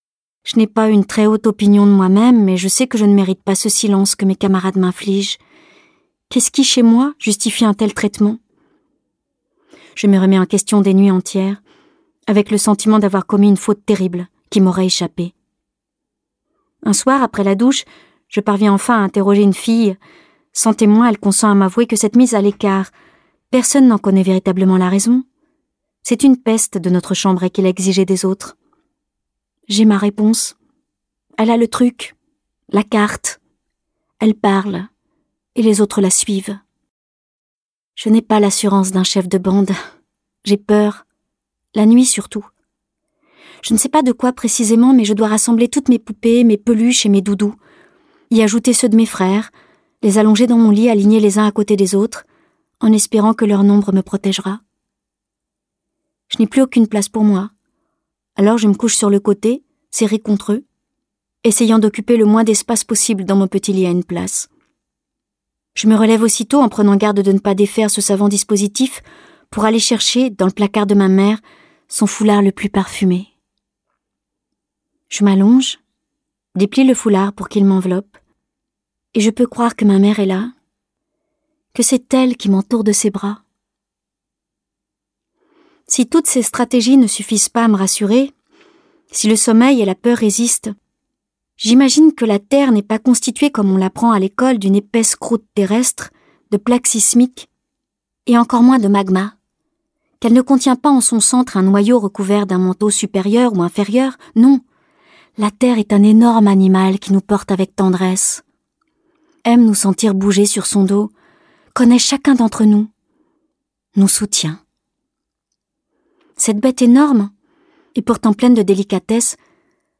L’occasion d’écouter le livre audio de ce titre, lu par Isabelle Carré elle-même.